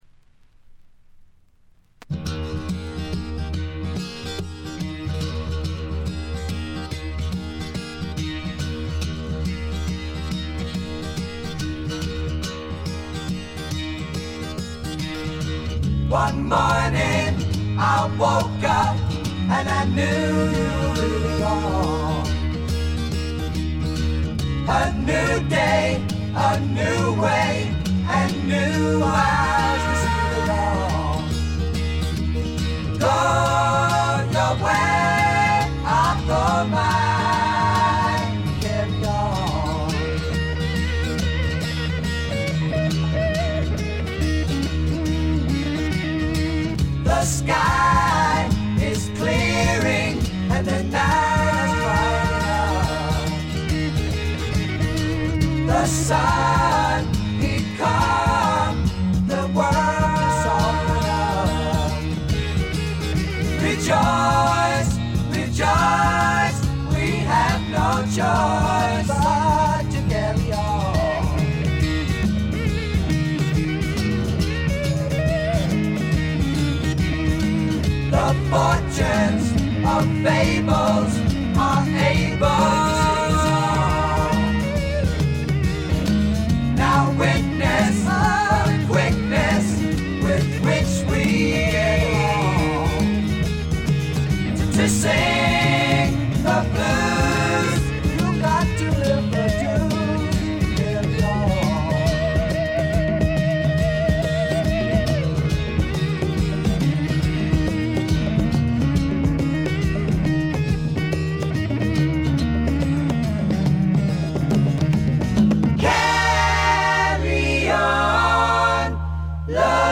部分試聴ですが静音部での微細なバックグラウンドノイズ程度。
試聴曲は現品からの取り込み音源です。